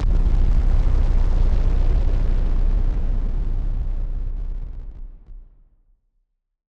BF_SynthBomb_D-04.wav